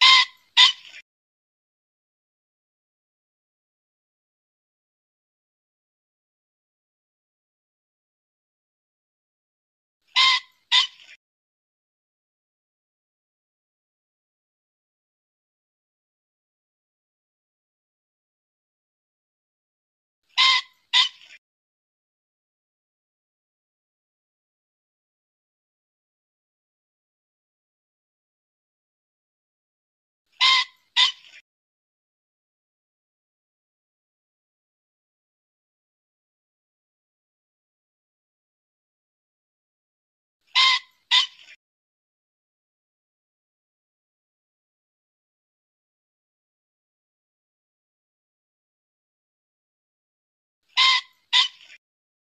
Tiếng chim Trĩ mp3